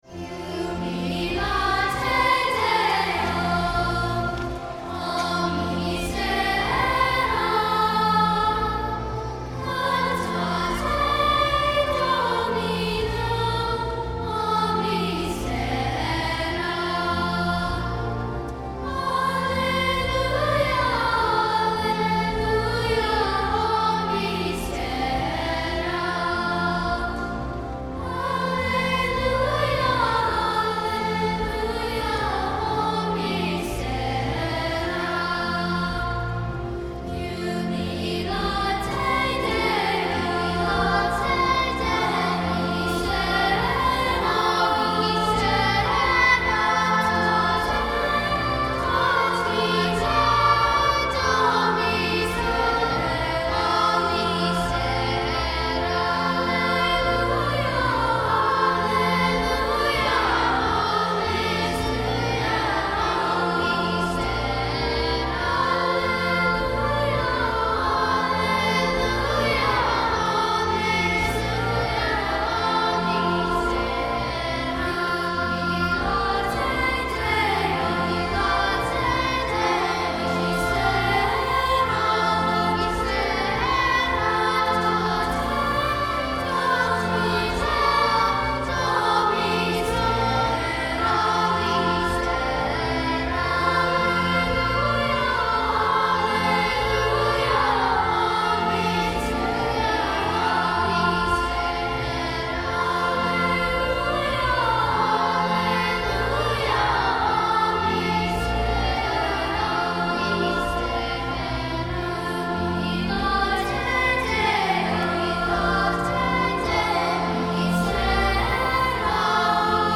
Mp3 Download • Live Children’s Choir Rec.
Ave Maria Parish Children’s Choir
It also includes an optional trumpet line that adds a festive, processional character.